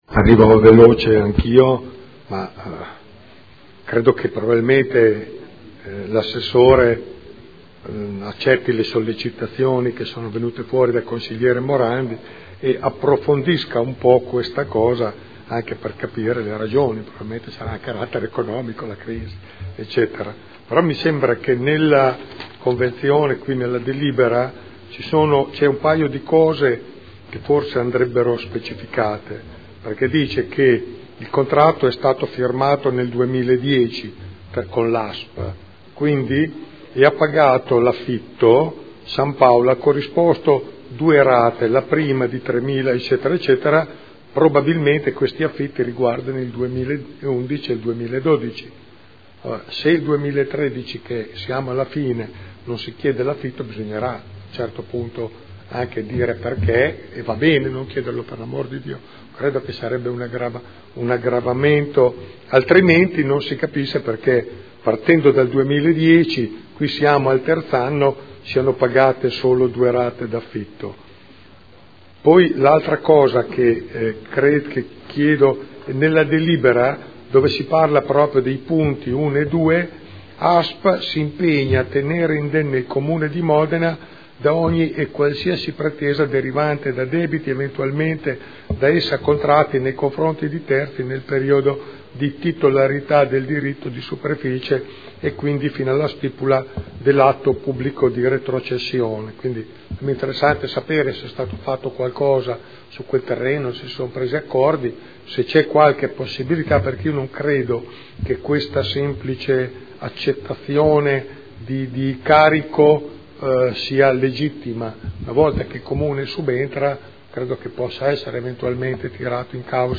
Seduta del 19 dicembre. Proposta di deliberazione: Estinzione anticipata del diritto di superficie costituito a favore di ASP San Paolo sugli immobili in Via dello Zodiaco. Dibattito